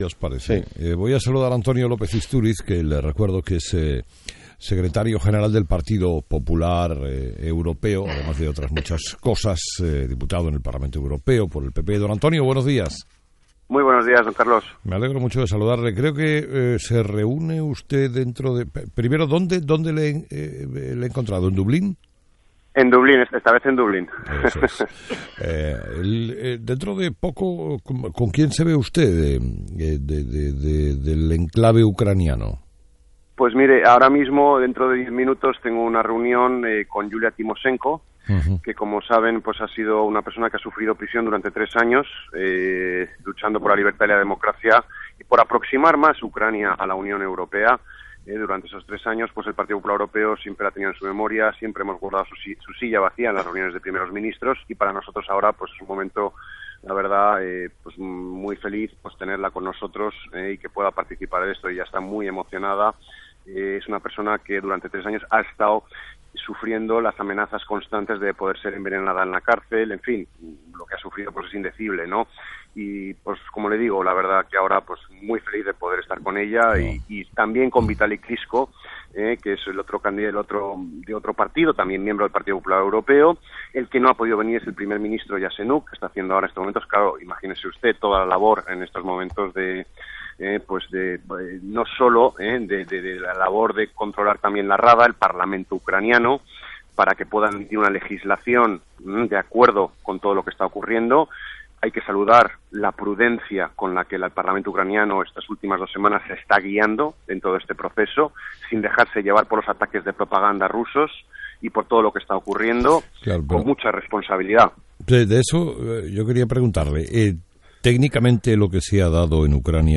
Entrevista a Antonio López Istúriz
Entrevistado: "Antonio López Istúriz"